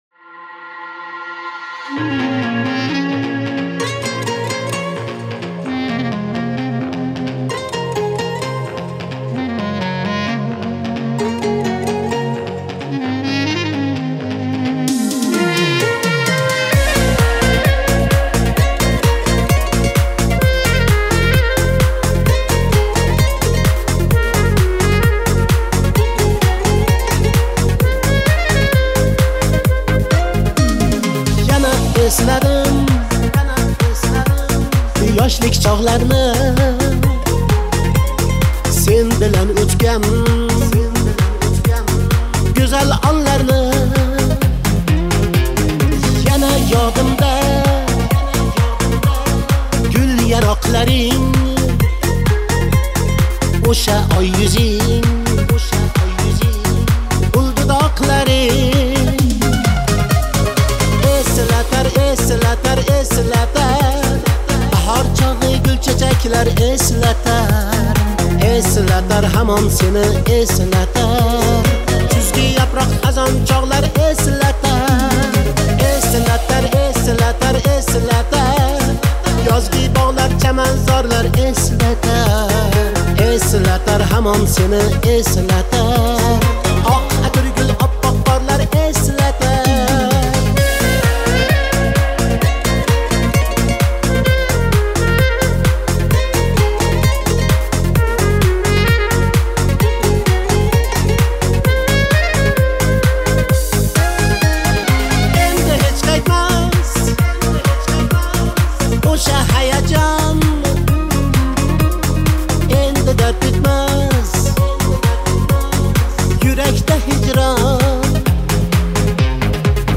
• Категория: Узбекские песни